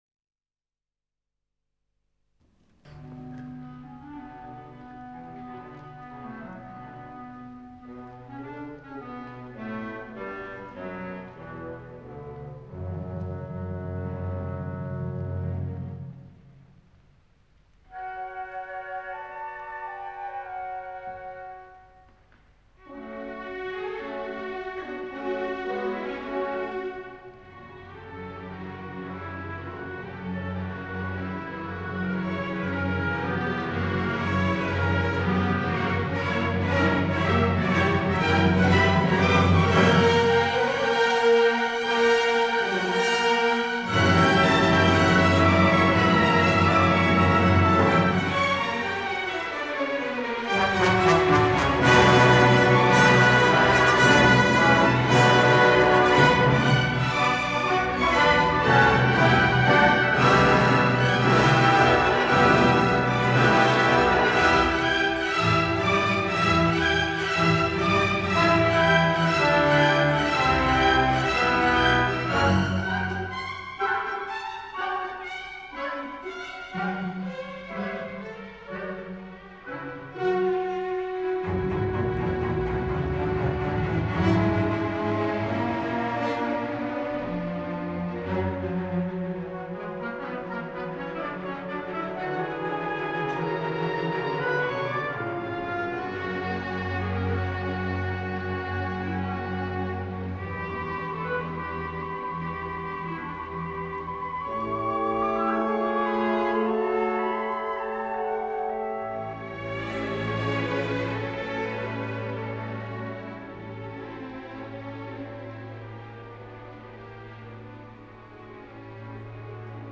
Pierre Fournier - Hermann Scherchen - Dvorak - 1962 - Past Daily Weekend Gramophone - Recorded for Italian-Swiss Radio.
More rarities from the vaults of Italian-Swiss Radio. This week it’s a performance of Dvorak’s Cello Concerto op 104 featuring cellist Pierre Fournier and the Orchestra of Radio Suisse Italienne, conducted by Hermann Scherchen, from a concert given on April 25, 1962.